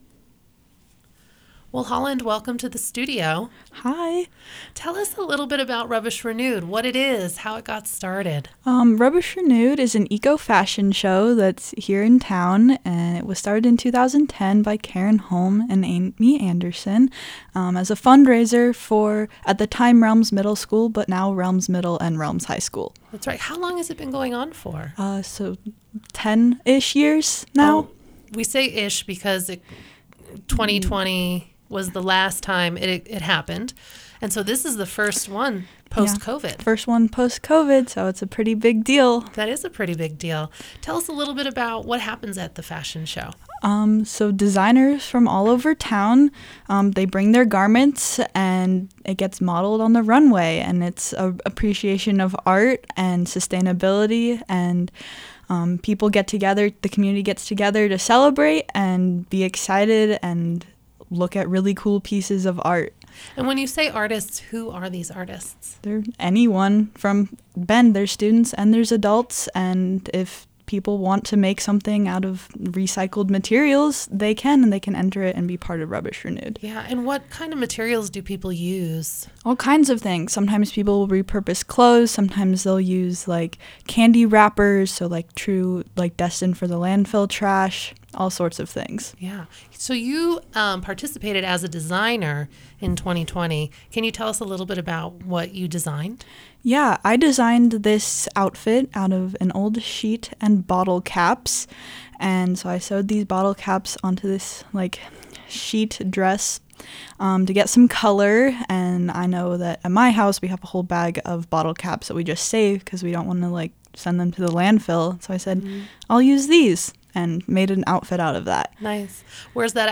KPOV Interview